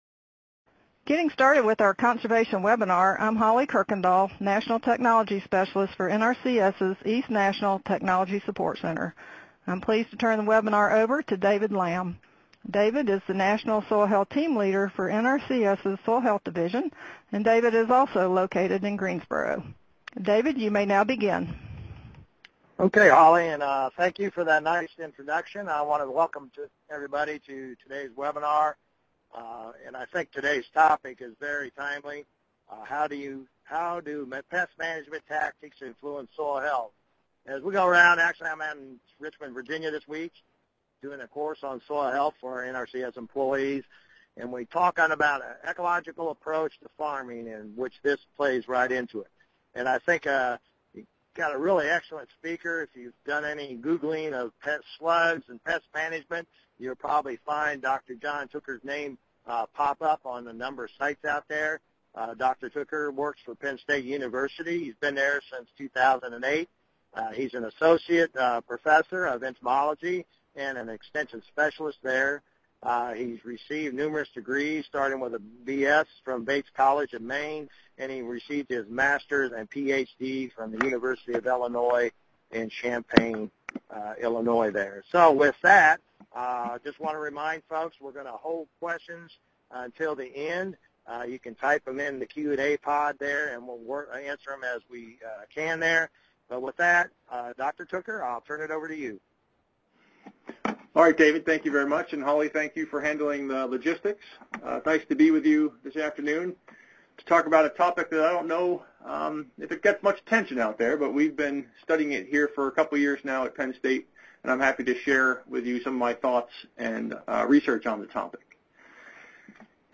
Streaming Podcast Webinar Audio - CEUs and certificates are not available for podcasts.